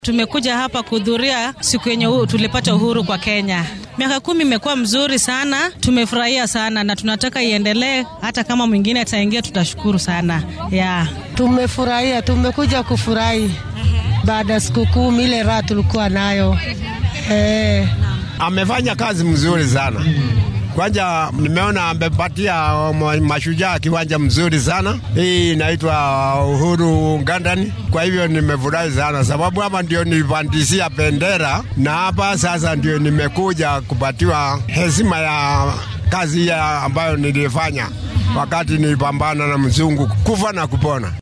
DHAGEYSO:Kenyaanka oo ka hadlay rajadooda ku aaddan khudbadda madaxweynaha ee maanta